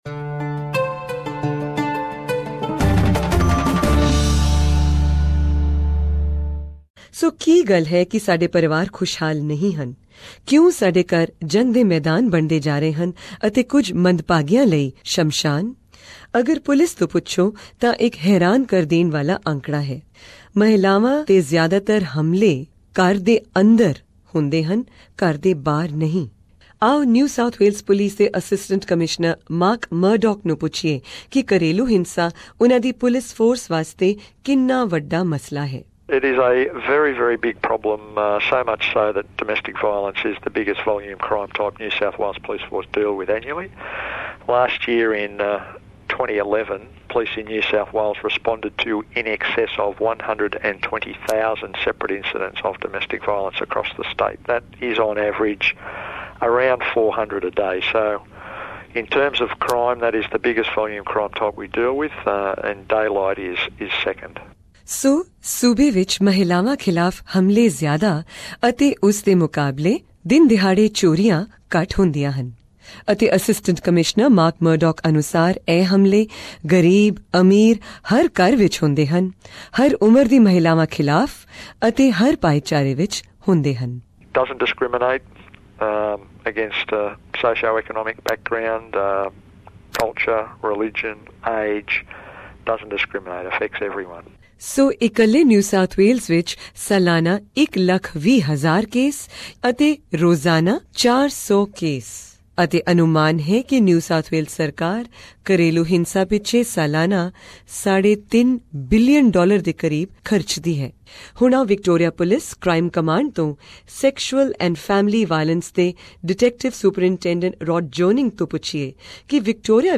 This is the third episode of our multi-award winning documentary on family violence in the Indian community of Australia, The Enemy Within. In this episode, we hear from senior police officials from NSW and Victoria about the statistics relating to family violence in their respective states and they also give us a clear definition of what constitutes family violence - its not just physical, but can occur at many other levels.